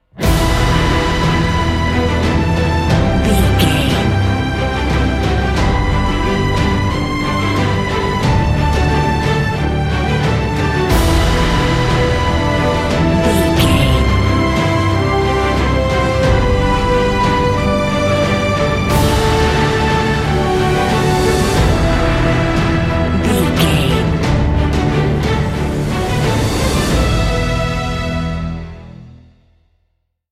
Aeolian/Minor
epic
mellow
brass
cello
drums
harp
strings
synthesizer